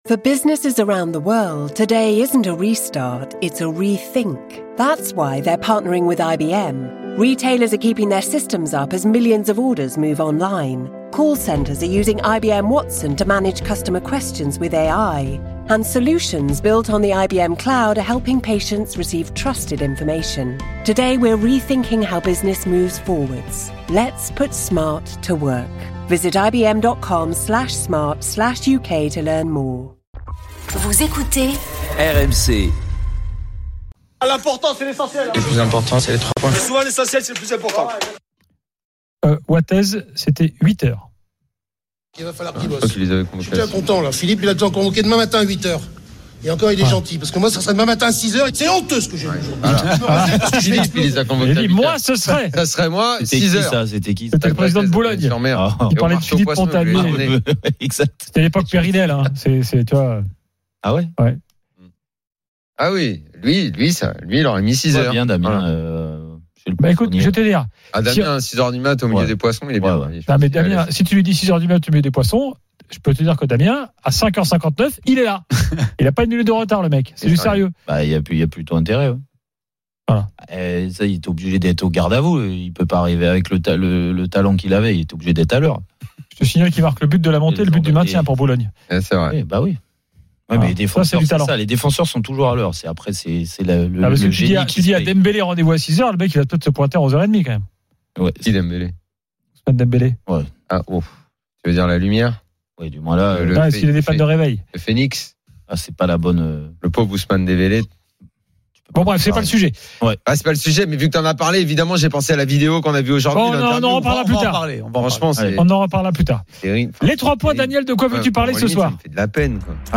Chaque jour, écoutez le Best-of de l'Afterfoot, sur RMC la radio du Sport !
Les rencontres se prolongent tous les soirs avec Gilbert Brisbois et Nicolas Jamain avec les réactions des joueurs et entraîneurs, les conférences de presse d'après-match et les débats animés entre supporters, experts de l'After et auditeurs.